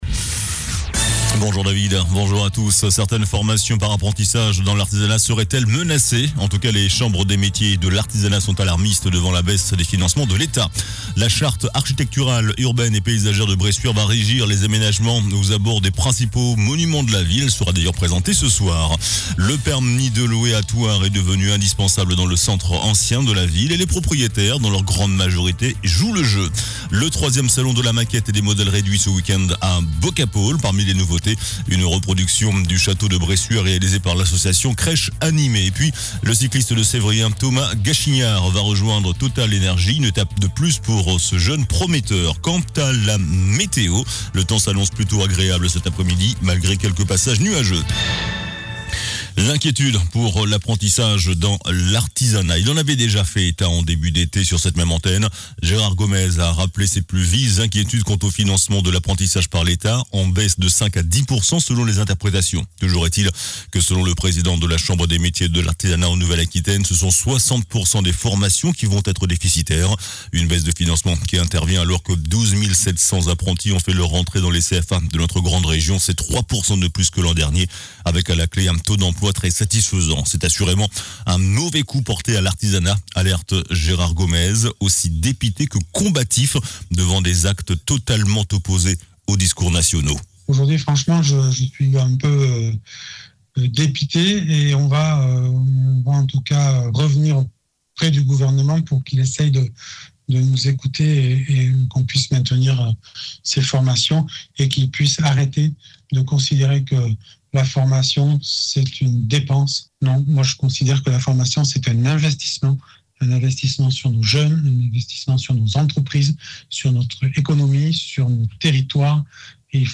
JOURNAL DU MERCREDI 20 SEPTEMBRE ( MIDI )